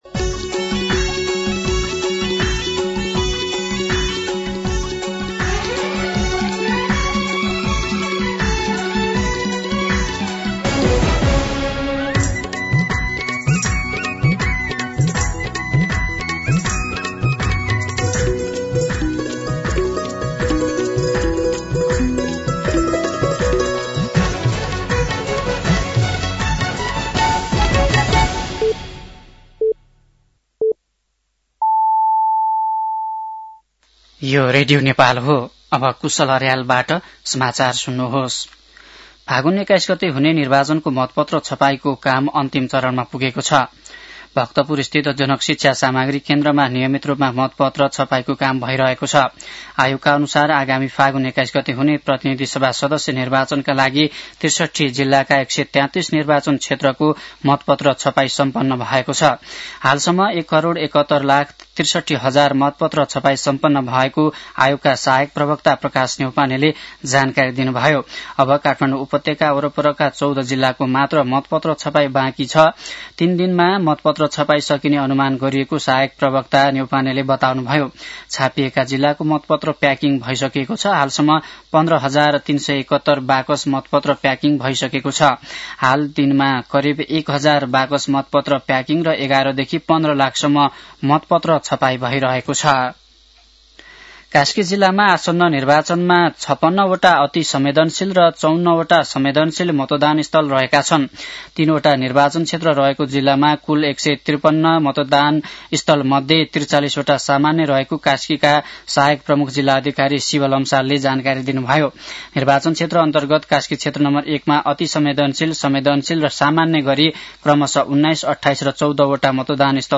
दिउँसो १ बजेको नेपाली समाचार : २ फागुन , २०८२
1-pm-Nepali-News-2.mp3